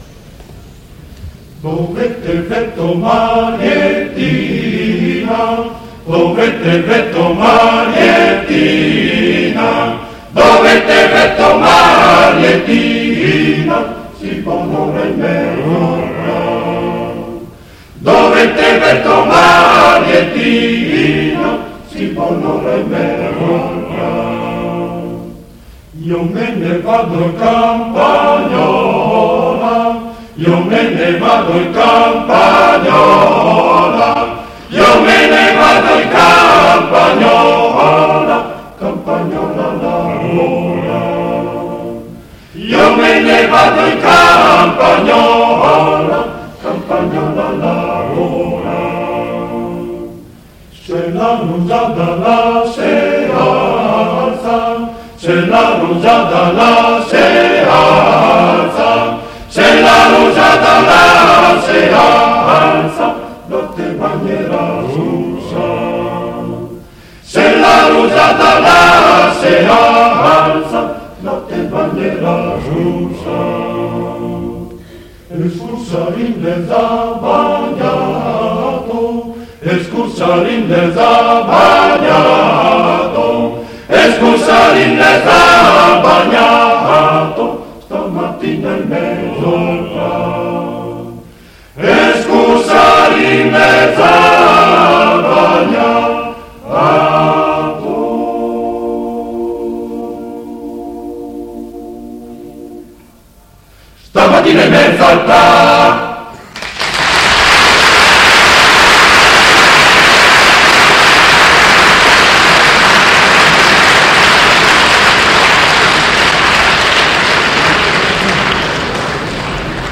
concerto del Coro della SAT, Torino, Conservatorio G. Verdi, 8 dicembre 1956
A.8.1.5 - Dove te vètt o Mariettina (Coro della SAT, Torino, Conservatorio G. Verdi, 8 dicembre 1956)